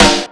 Medicated Snare 17.wav